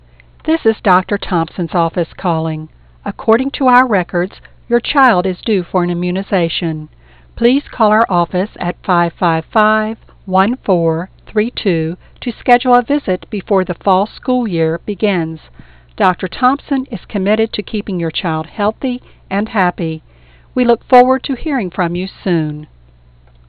And you'll deliver your messages with professionally recorded, crystal-clear 16 bit sound.